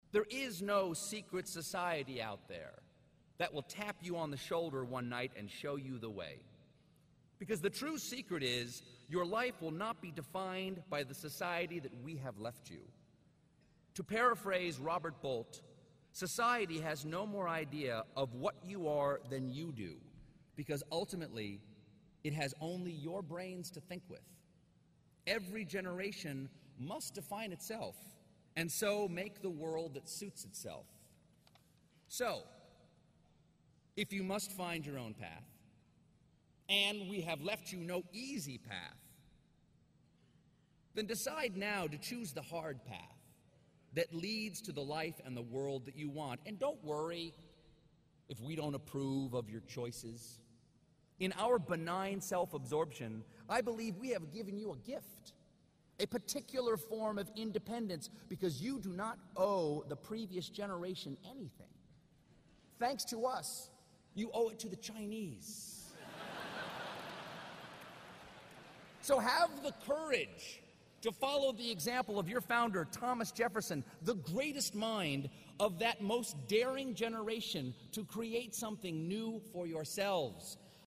公众人物毕业演讲 第257期:斯蒂芬科尔伯特2013弗吉尼亚大学(10) 听力文件下载—在线英语听力室